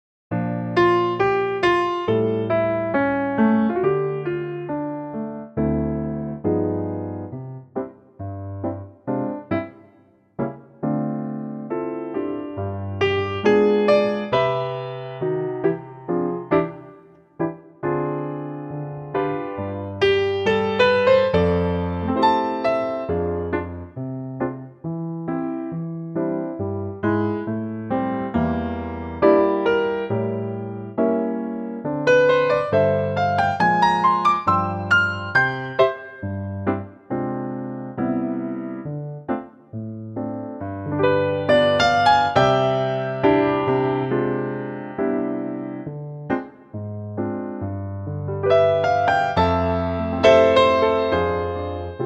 Unique Backing Tracks
key - C - vocal range - D to E